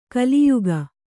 ♪ kaliyuga